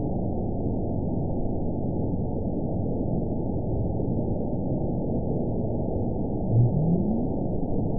event 918179 date 07/28/23 time 18:27:44 GMT (2 years, 3 months ago) score 9.48 location TSS-AB03 detected by nrw target species NRW annotations +NRW Spectrogram: Frequency (kHz) vs. Time (s) audio not available .wav